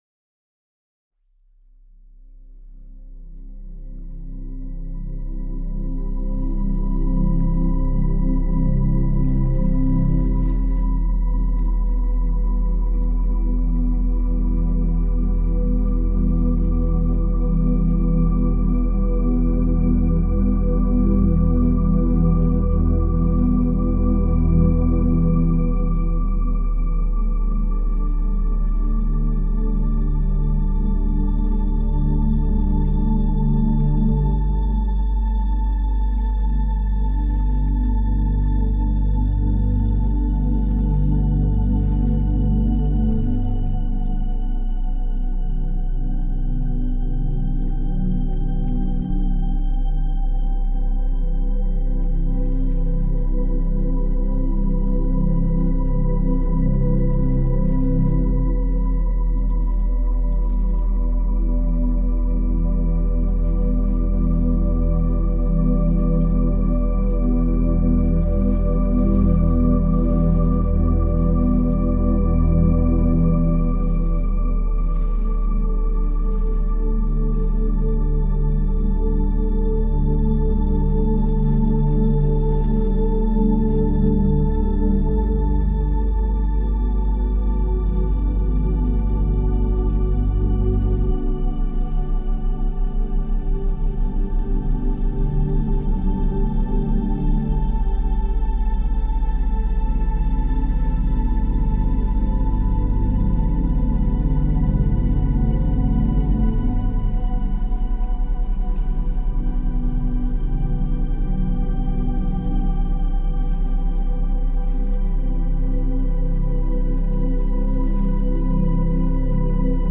Musique-pour-s-endormir-1.mp3